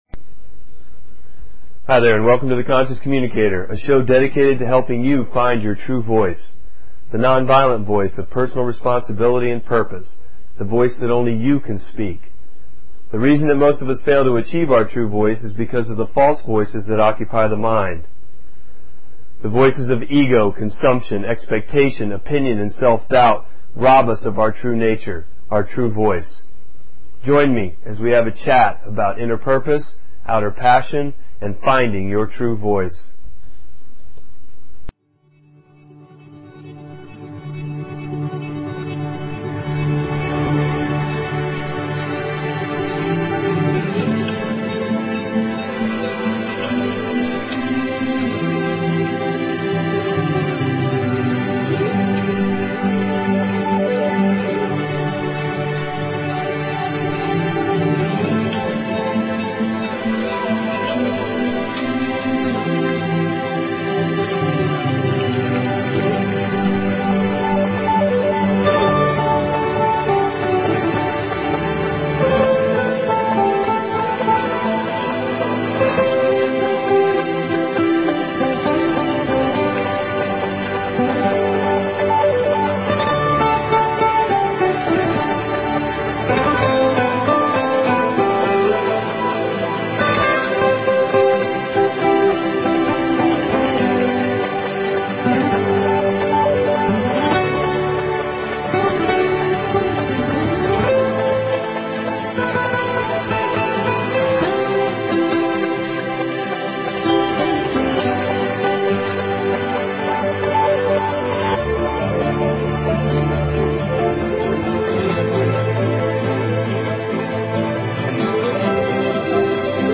Talk Show Episode, Audio Podcast, The_Conscious_Communicator and Courtesy of BBS Radio on , show guests , about , categorized as